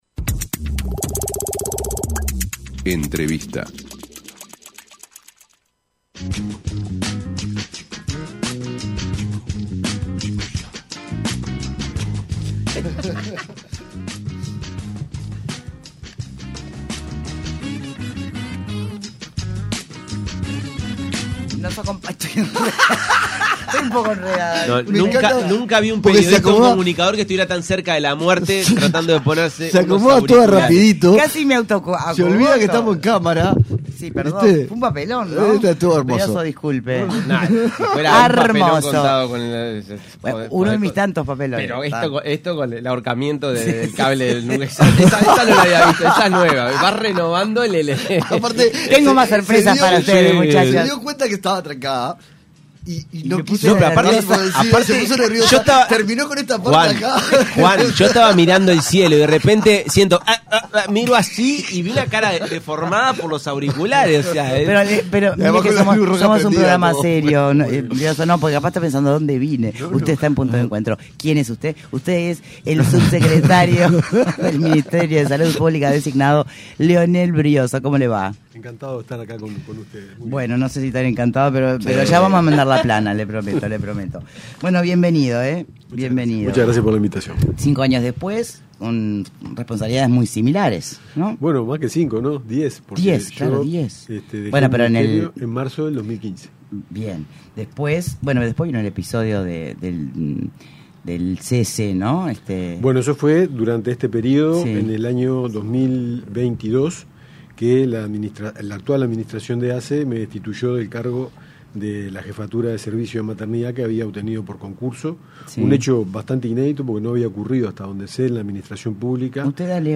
Entrevista a Leonel Briozzo El futuro subsecretario de Salud Pública, Leonel Briozzo se refirió en entrevista con Punto de Encuentro a sus declaraciones vinculadas al aborto, la “felicidad pública” y el “alivio social”.
El futuro subsecretario de Salud Pública, Leonel Briozzo se refirió en entrevista con Punto de Encuentro a sus declaraciones vinculadas al aborto, la “felicidad pública” y el “alivio social”.